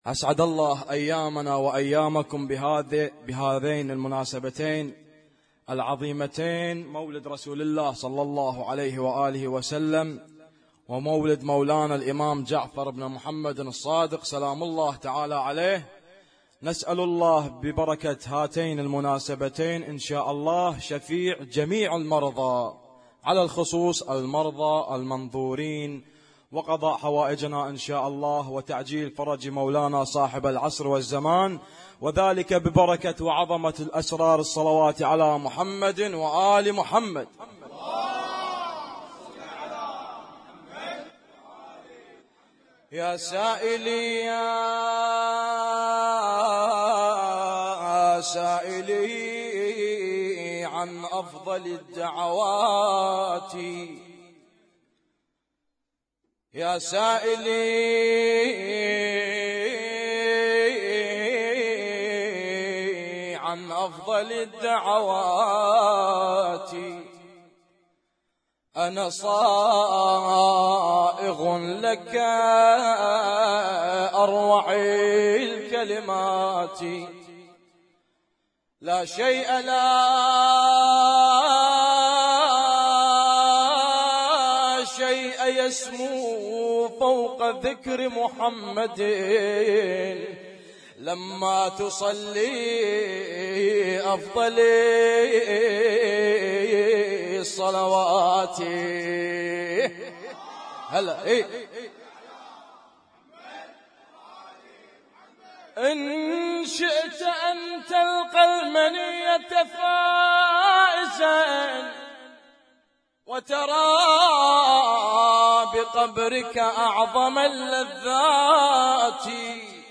Husainyt Alnoor Rumaithiya Kuwait
اسم التصنيف: المـكتبة الصــوتيه >> المواليد >> المواليد 1437